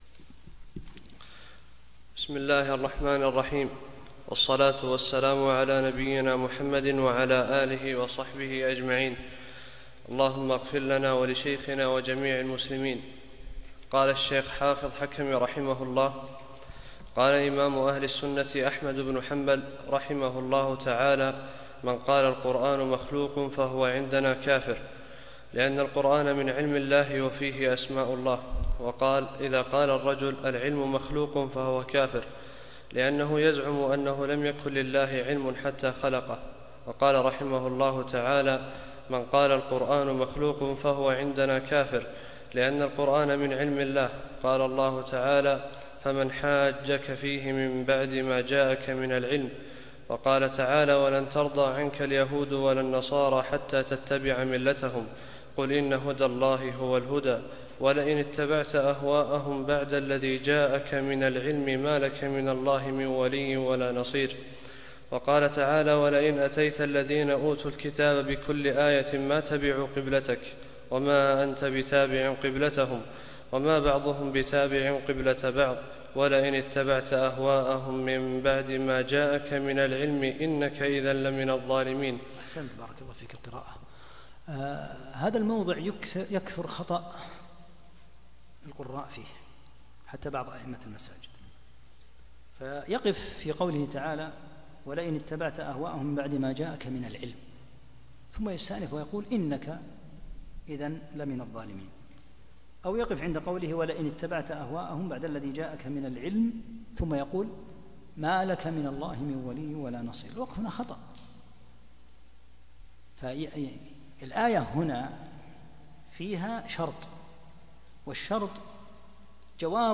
38- الدرس الثامن والثلاثون